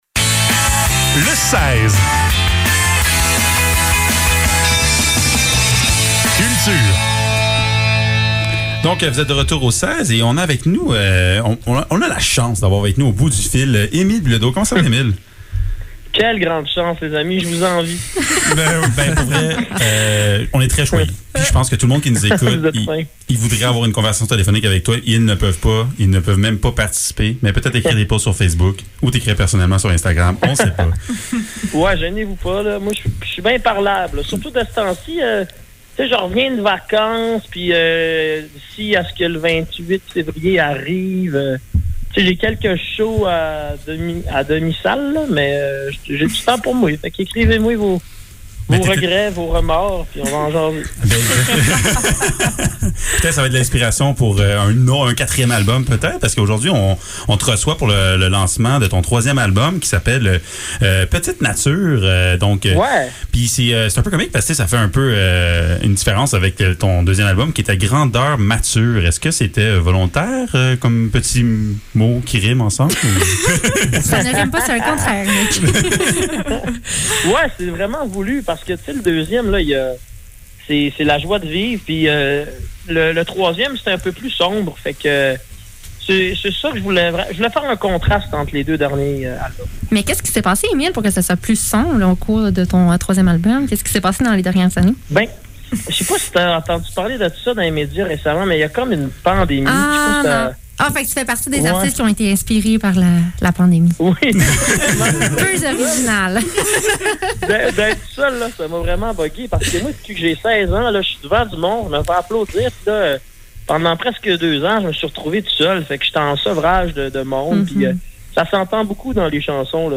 Le seize - Entrevue avec Émile Bilodeau - 9 février 2022